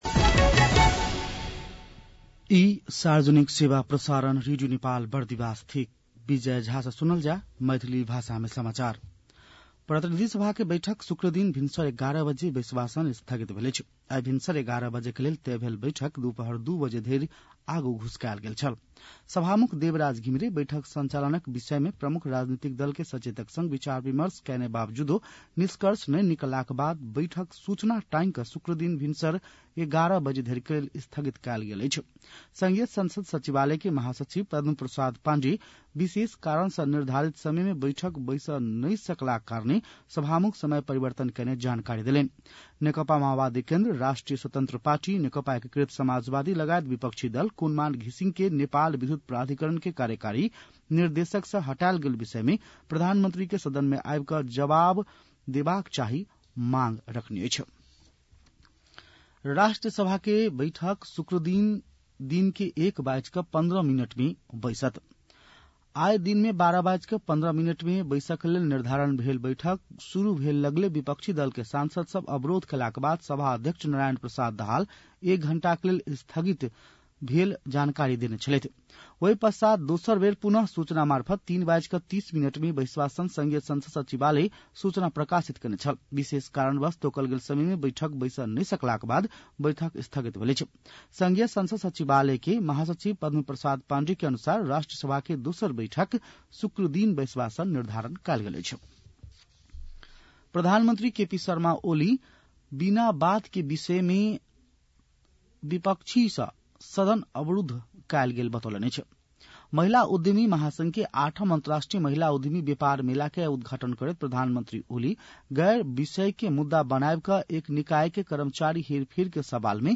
मैथिली भाषामा समाचार : १४ चैत , २०८१
Maithali-news-12-14.mp3